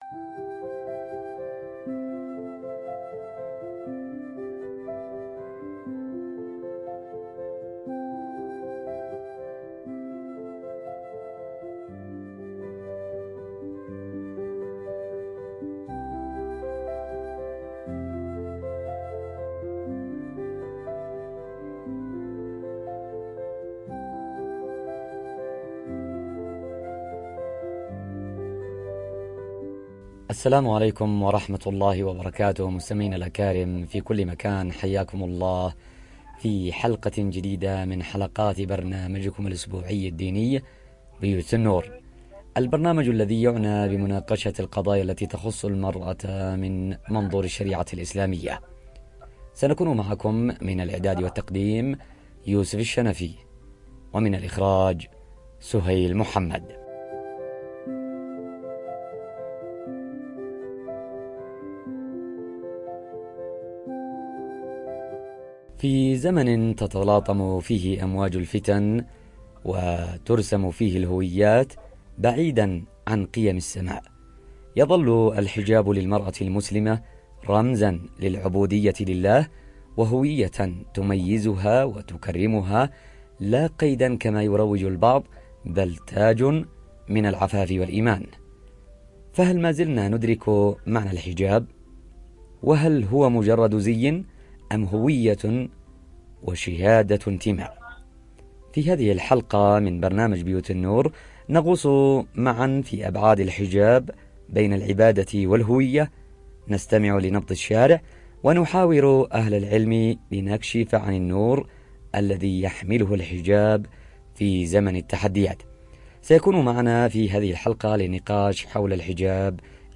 في نقاش فكري وحقوقي
عبادة وهوية" 📅 الجمعة ⏰ الساعة 10:00 صباحاً 📻 عبر أثير إذاعة رمز 📌 لا تفوّتوا هذه الحلقة التي تعيد تعريف الحجاب في سياق الكرامة والاختيار، و تثبت أن الحجاب ليس تنازلًا عن الحرية، بل تعبيرٌ عنها.